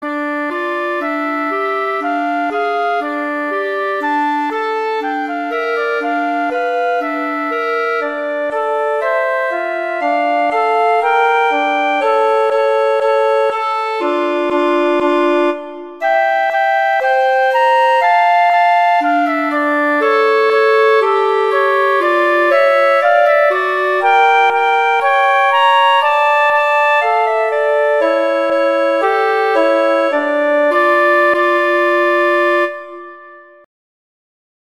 Instrumentation: flute & clarinet
arrangements for flute and clarinet